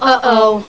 A small collection of sounds from 'Sister Sister'.
All voices by Tia and Tamera.
ohoh.wav